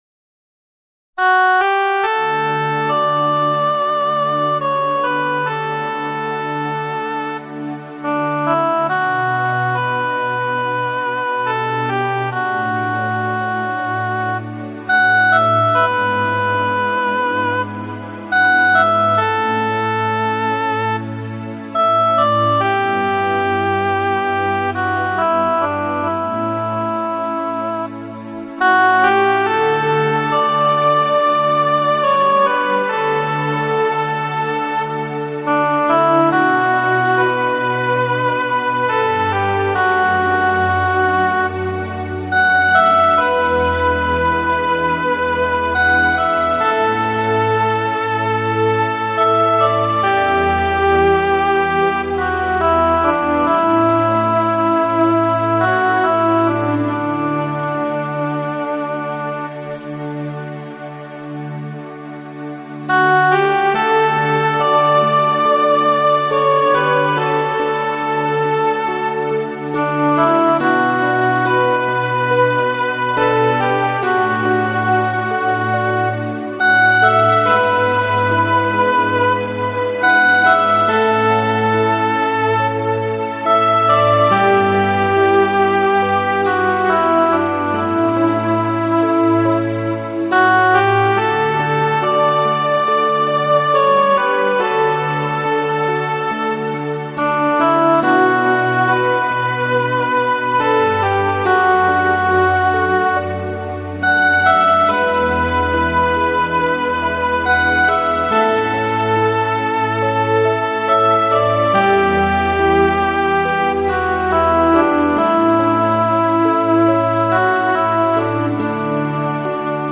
尽情倾听来自海涛的节奏与韵律......